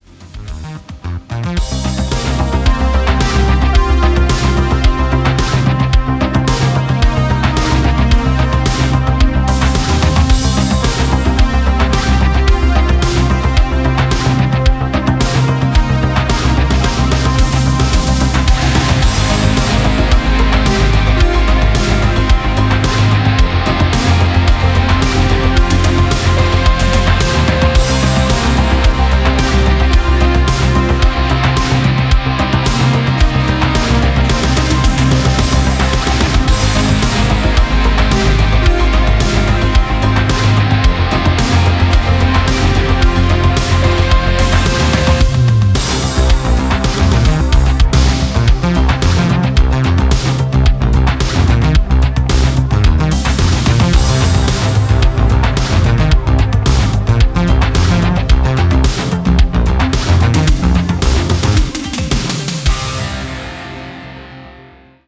Electronic
synthwave
Darkwave
ретро
darksynth
Cyberpunk